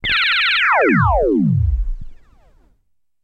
Synth Zaps
Synth Energy Zap Blast Away